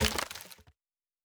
Wood 03.wav